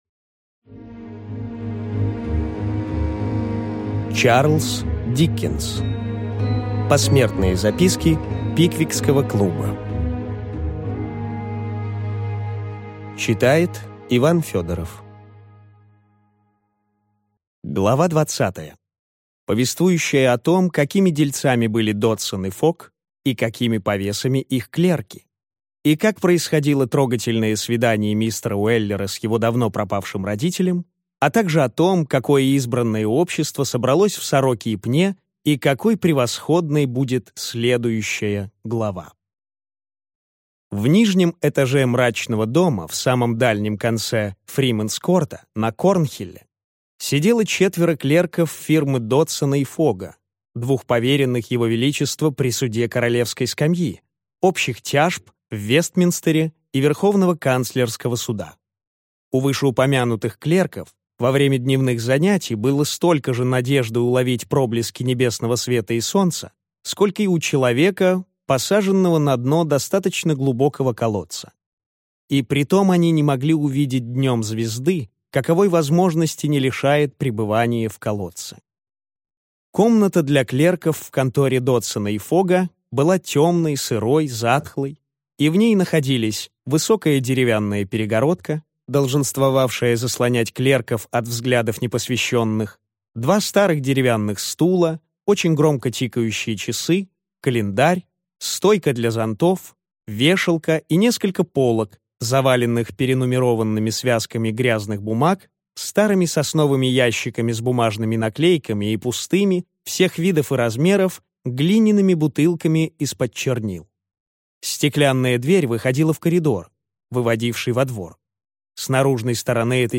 Аудиокнига Посмертные записки Пиквикского клуба (Часть 2) | Библиотека аудиокниг